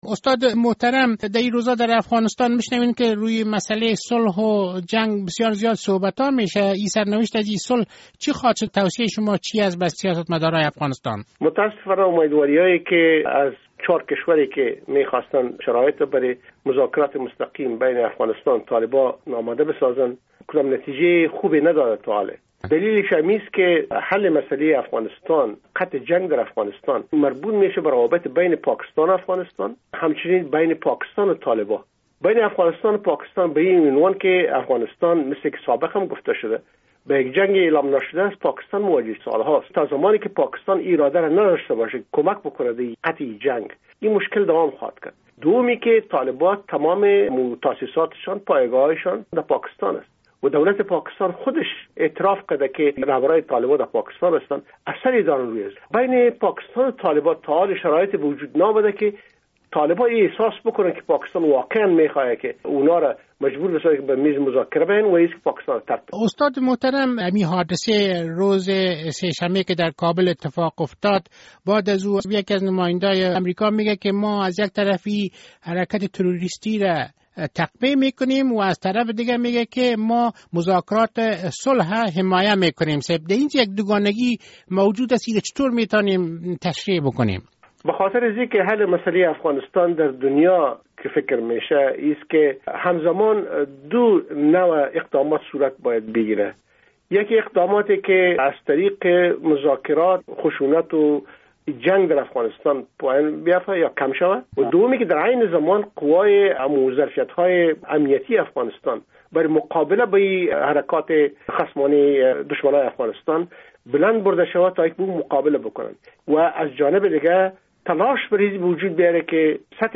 مصاحبه با داکتر علی احمد جلالی استاد در پوهنتون دفاع ملی امریکا در واشنگتن راجع به مذاکرات صلح با طالبان و وارد کردن فشار بالای پاکستان